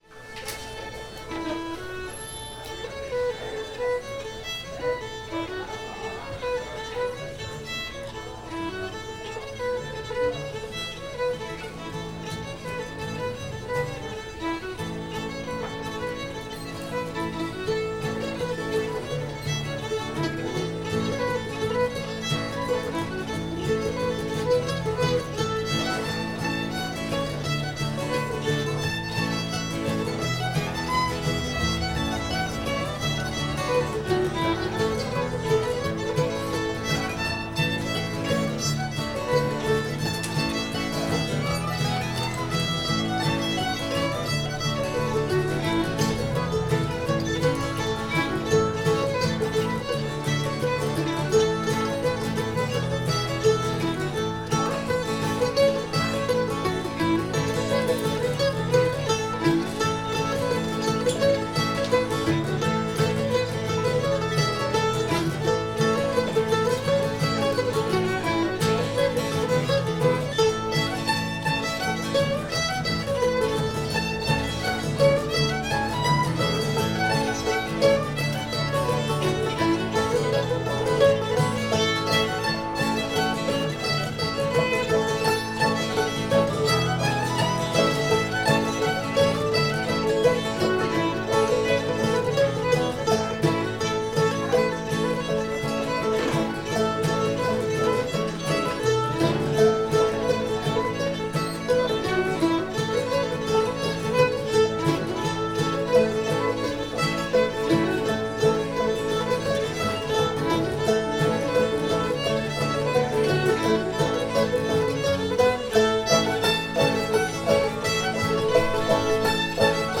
tar river [A]